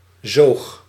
Ääntäminen
Ääntäminen Tuntematon aksentti: IPA: /zoːx/ Haettu sana löytyi näillä lähdekielillä: hollanti Käännöksiä ei löytynyt valitulle kohdekielelle.